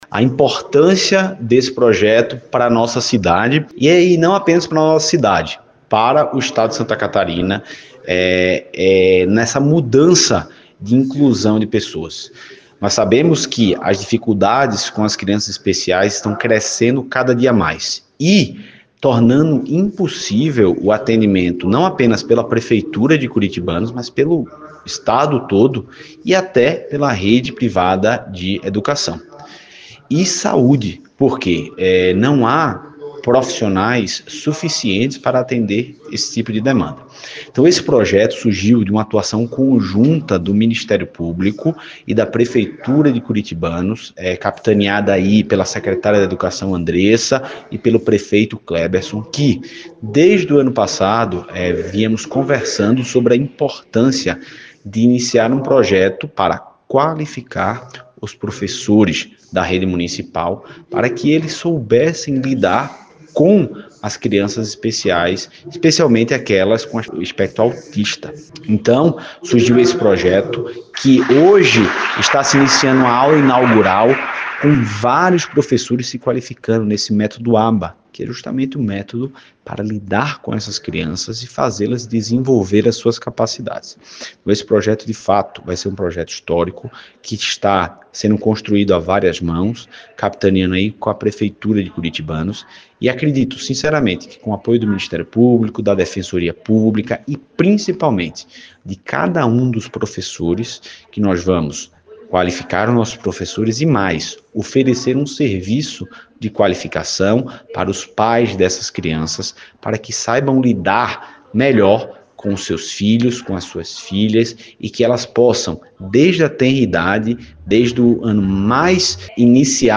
A aula inaugural do curso que contou com especialistas de São Paulo, teve também a presença do Promotor de Justiça da 3ª Promotoria de Justiça de Curitibanos, Dr. Renato Maia de Faria, parceiro do município na construção da lei recentemente aprovada.
promotor-aba.mp3